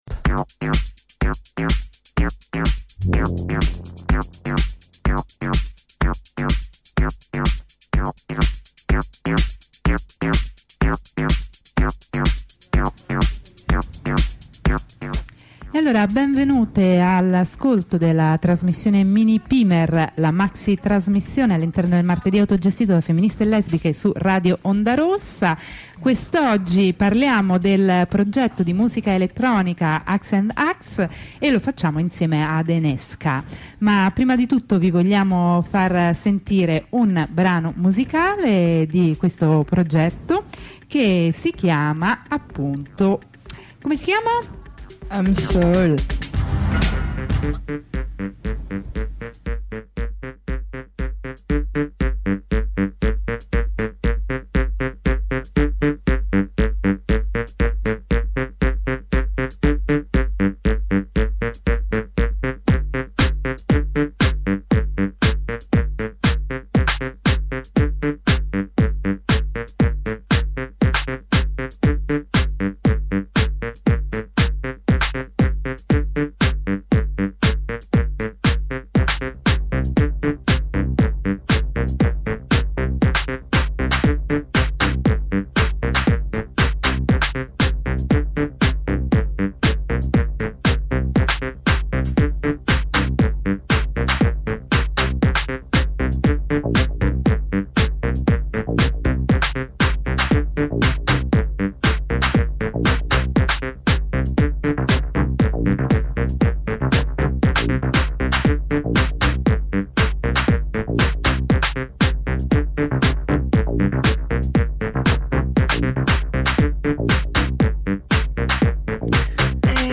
musica elettronica | Radio Onda Rossa